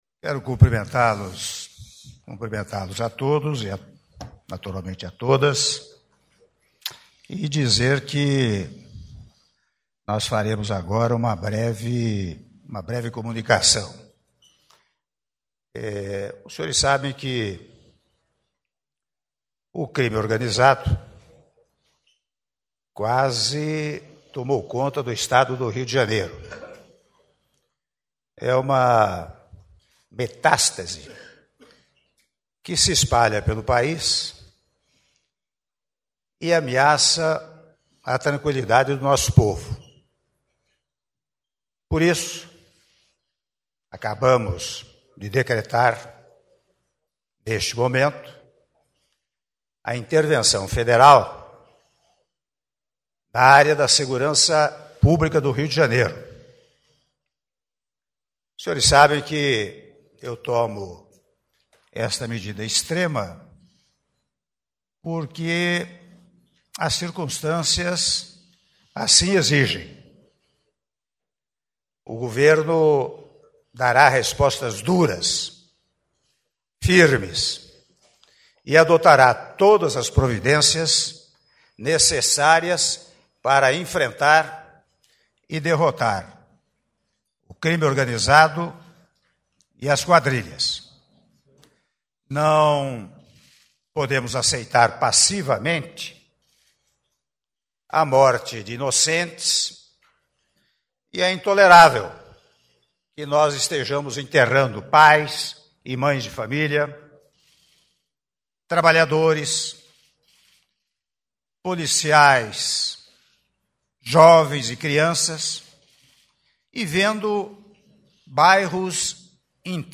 Áudio do discurso do Presidente da República, Michel Temer, durante assinatura do Decreto de Intervenção Federal na Segurança Pública do Rio de Janeiro- Brasília/DF - (07min21s)